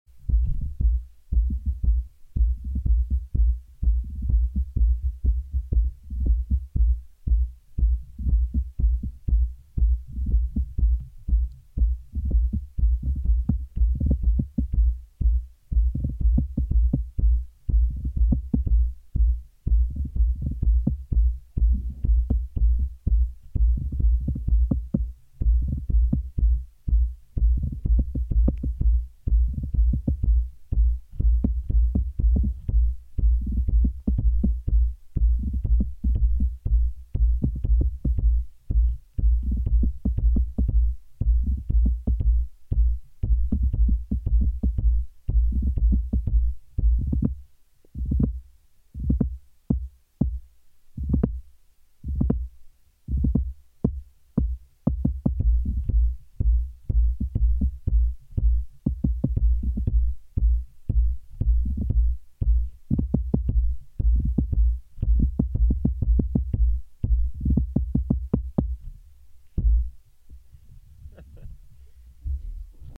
Here is a video of sound effects free download By cosmosheldrake 85 Downloads 12 months ago 72 seconds cosmosheldrake Sound Effects About Here is a video of Mp3 Sound Effect Here is a video of me playing the roots of what might be the oldest tree in the world.
The tree lives in Alerce Costero National Park and is a Fitzroya cupressoides